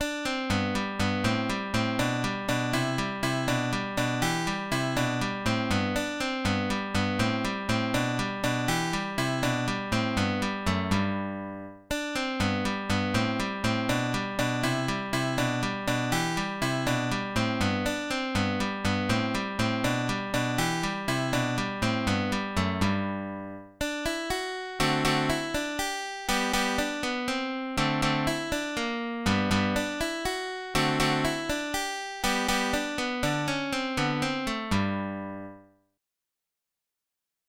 MONFERRINA
—>È caratterizzata da un tempo di 6/8.